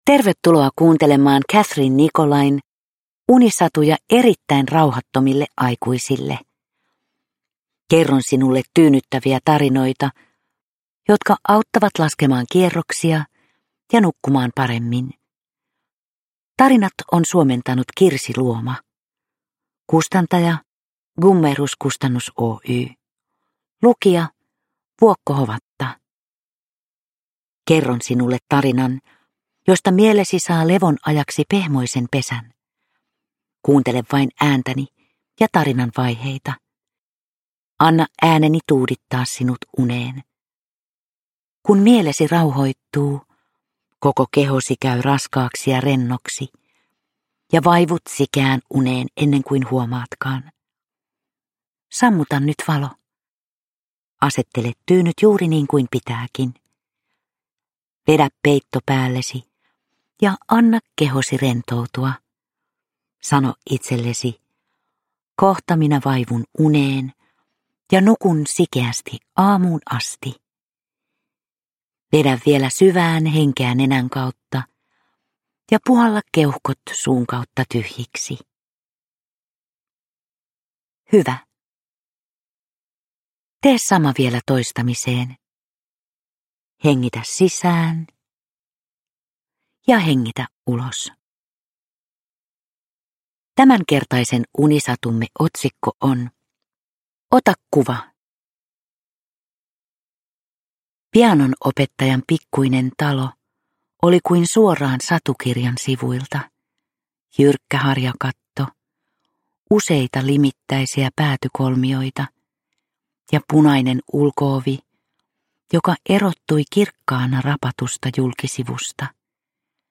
Unisatuja erittäin rauhattomille aikuisille sisältää kymmenen itsenäistä mutta toisiinsa nivoutuvaa tarinaa tyynnyttämään mieltä ja helpottamaan nukahtamista. Ne sijoittuvat yhden kadun varrelle kesän viimeisenä viikonloppuna ja osoittavat, kuinka olemme jatkuvasti yhteydessä toisiimme pienin mutta merkityksellisin tavoin. Vuokko Hovatan tyyni ääni saattelee kuulijan lempeästi unten maille.
• Ljudbok